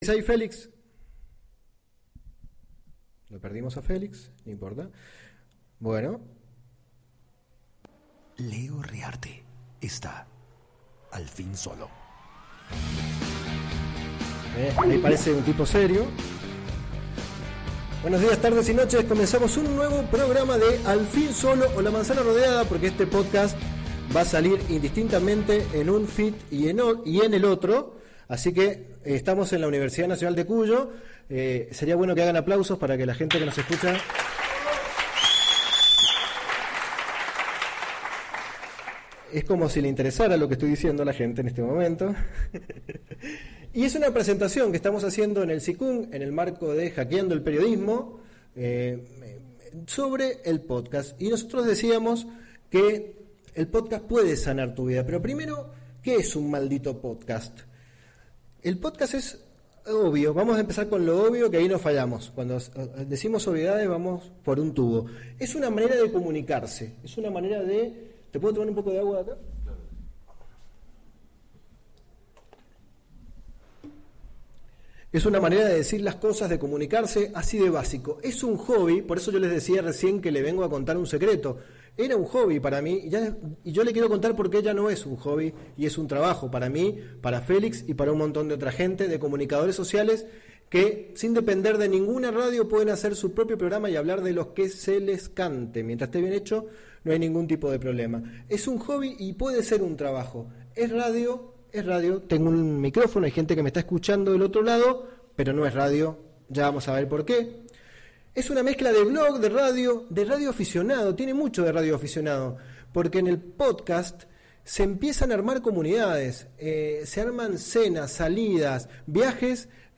El podcast puede sanar tu vida. Charla en la Universidad Nacional de Cuyo.
Una charla que di en la Uncuyo Mendoza sobre el Podcasting.